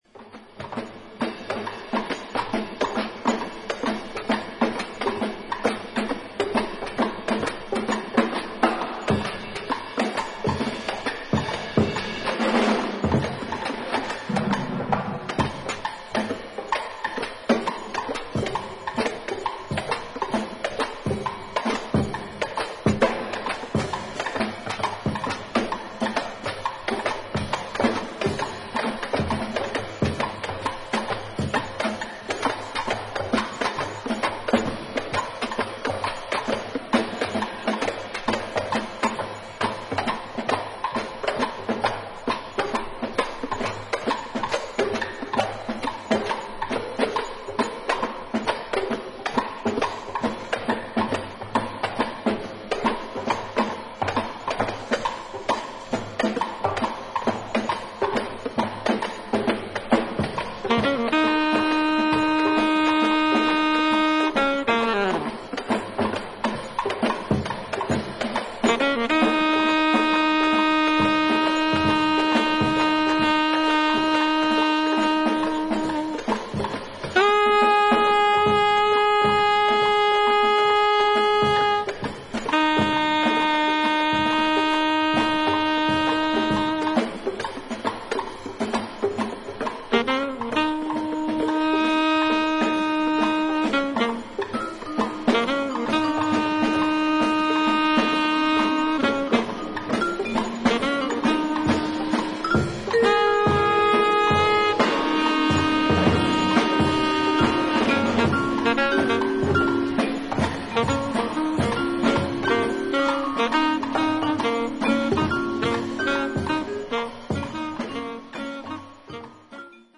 ドラムと民族的な打楽器によるダンサブルなリズム・ワークが冴える